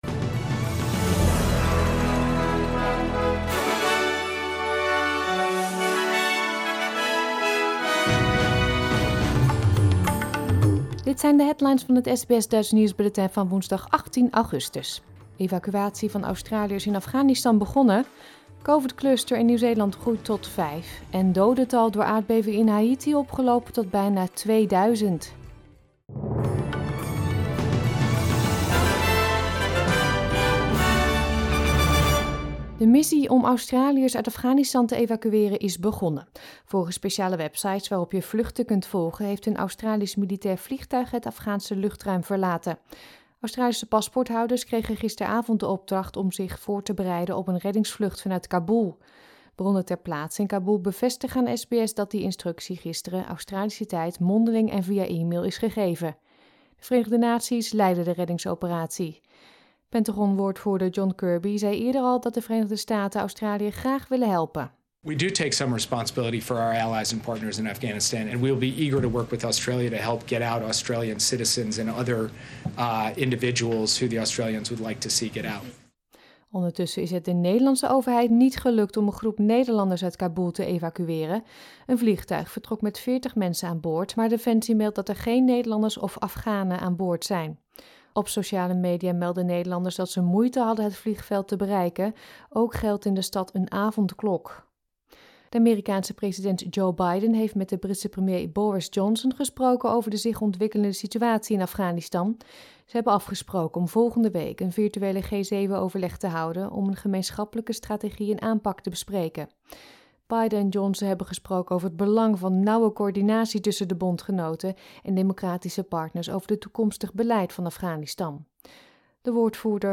Nederlands/Australisch SBS Dutch nieuwsbulletin van woensdag 18 augustus 2021